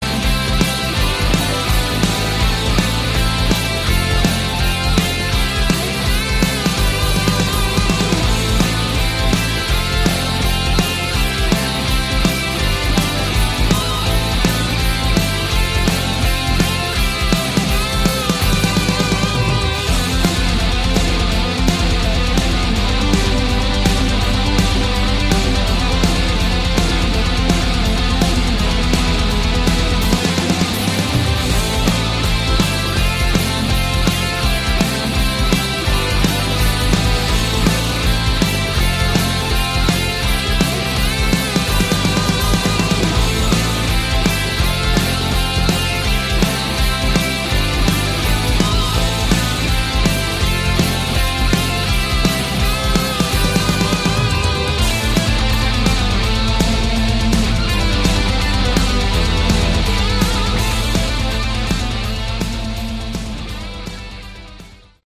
Category: Melodic Rock
vocals
bass
keyboards
lead and rhythm guitar
drums